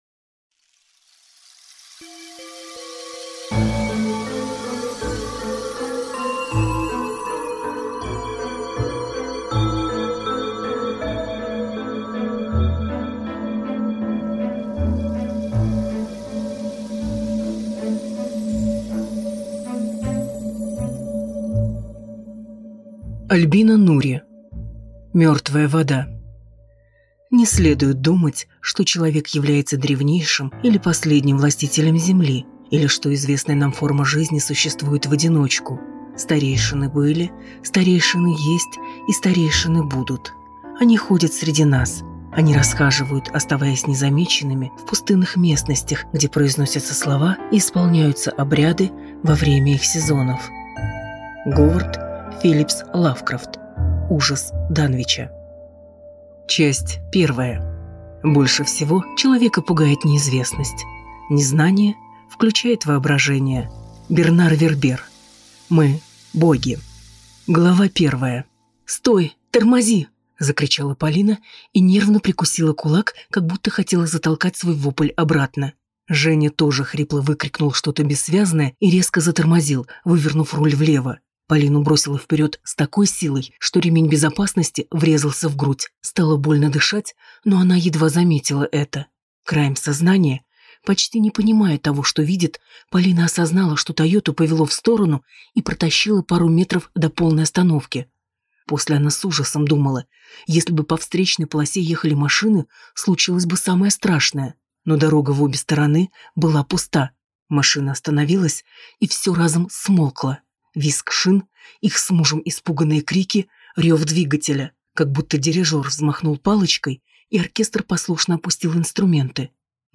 Аудиокнига Мертвая вода | Библиотека аудиокниг
Прослушать и бесплатно скачать фрагмент аудиокниги